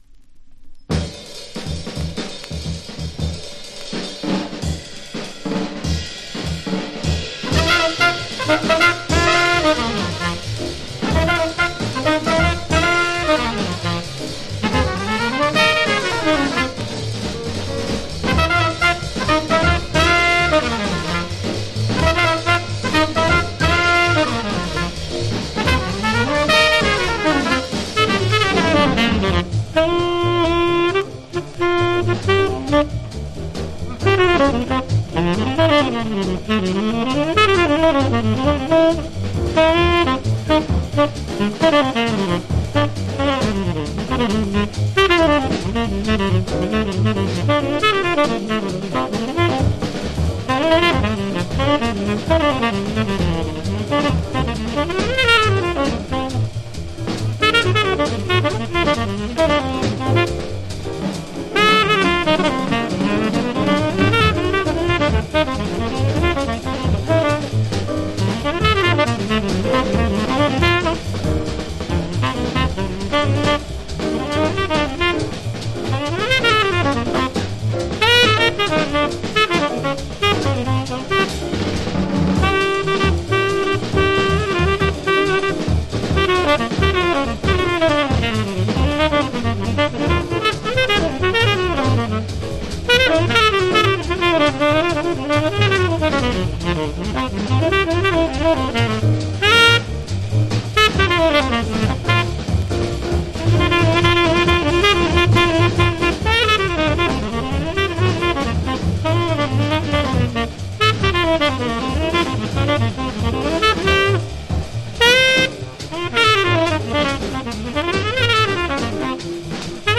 （小傷によりチリ、プチ音ある曲あり）(B-1 少し周回ノイズ、B-2 周回ノイズ)
MONO
Genre US JAZZ